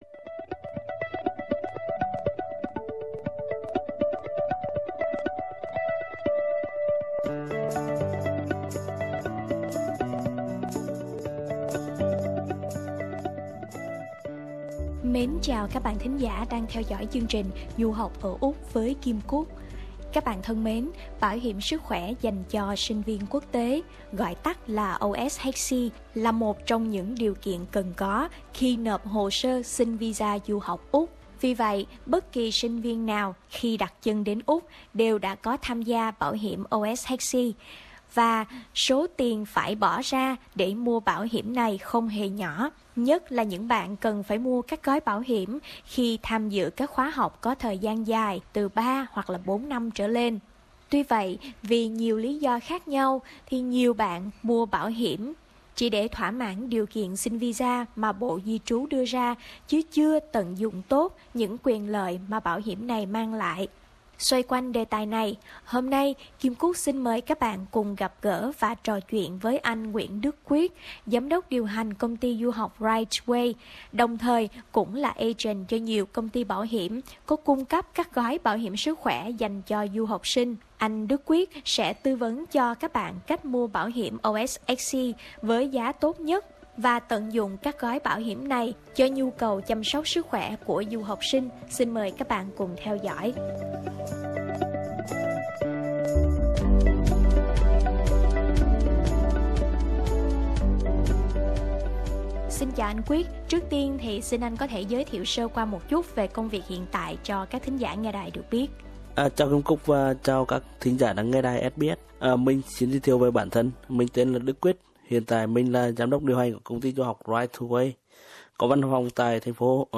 đồng thời cũng là agent cho nhiều công ty bảo hiểm có cung cấp các gói bảo hiểm sức khỏe dành cho du học sinh trò chuyện với SBS Vietnamese về cách mua OSHC và tận dụng các gói bảo hiểm này cho nhu cầu chăm sóc sức khỏe.